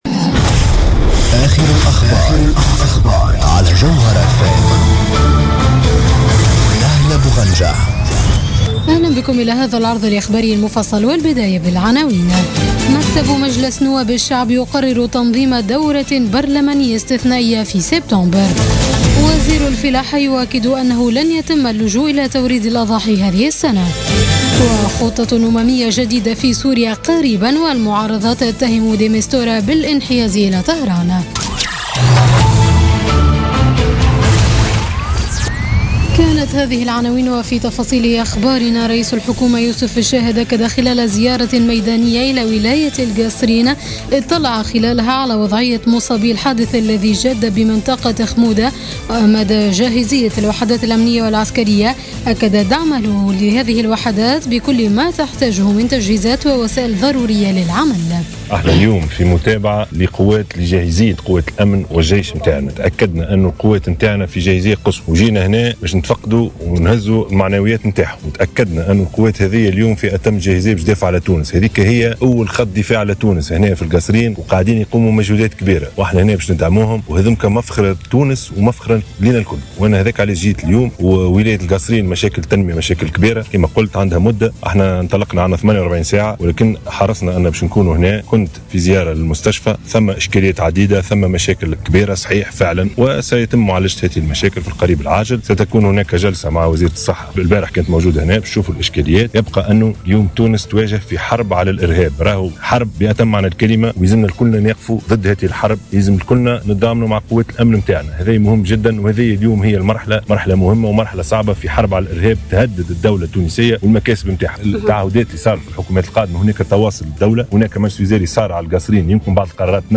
نشرة أخبار منتصف الليل ليوم الجمعة 2 سبتمبر 2016